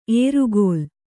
♪ ērugōlu